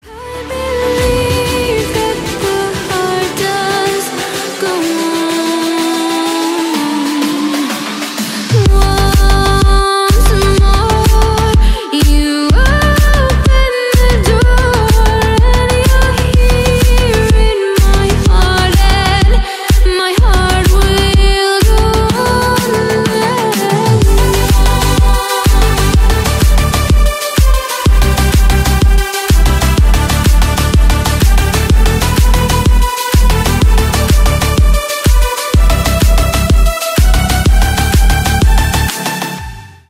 Ремикс
кавер